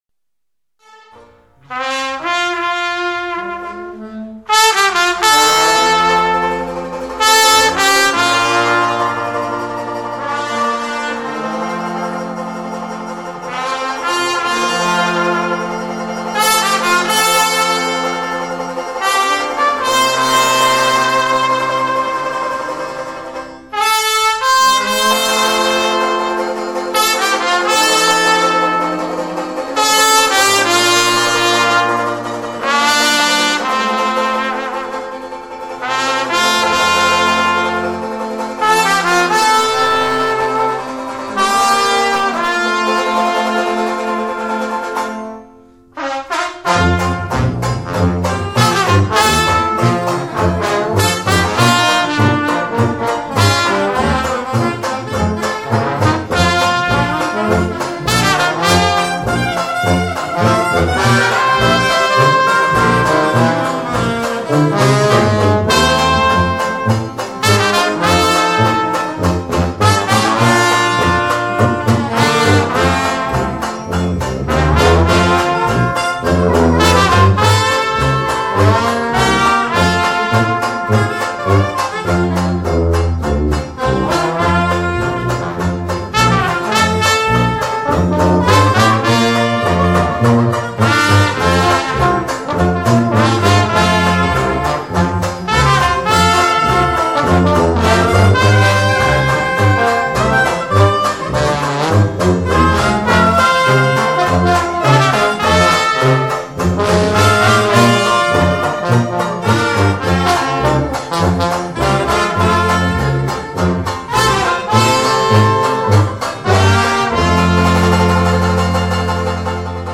特制立体声录音